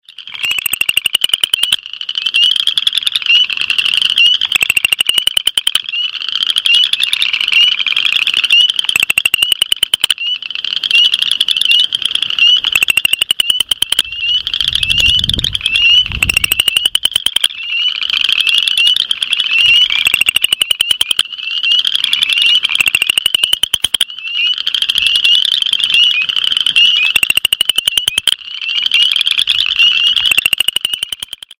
Boreal Chorus Frog (Pseudacris maculata)